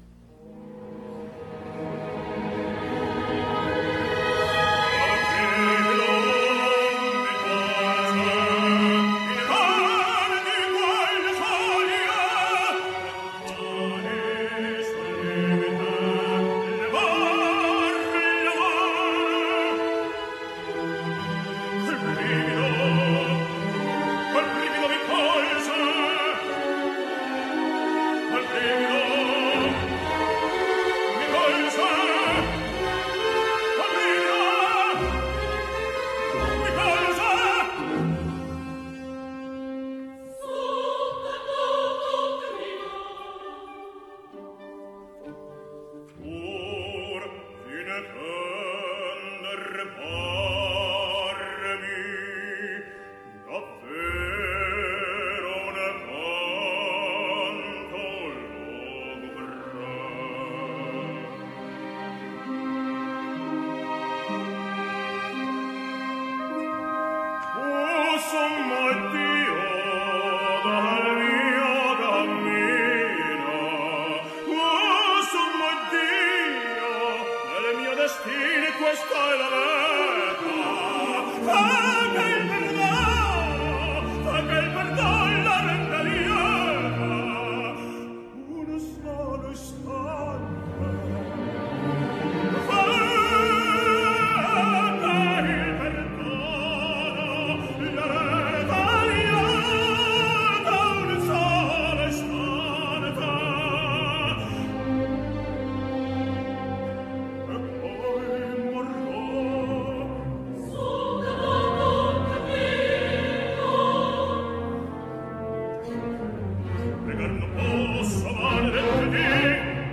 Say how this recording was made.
Concert Version Recorded Live